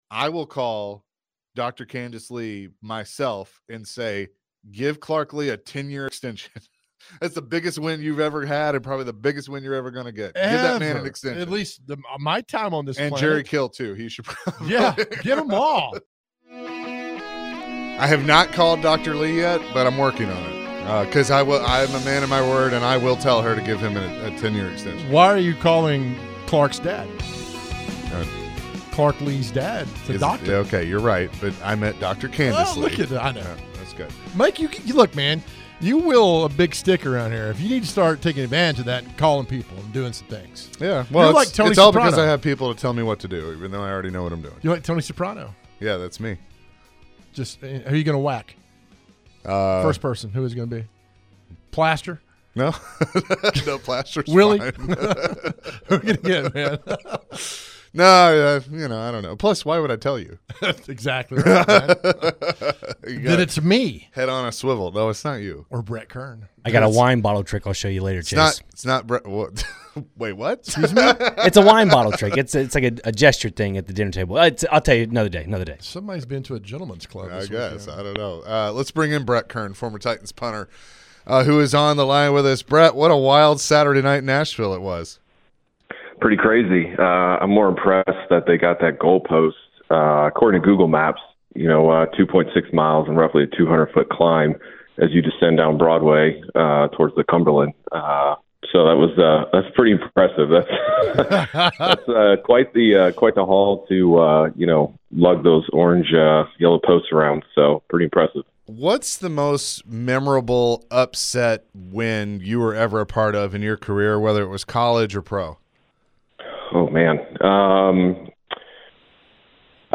Former Titans Punter Brett Kern joined the show and shared his thoughts on Vanderbilt's win over the Tide. Later in the conversation, Brett mentioned what might need to change for the Titans special teams unit.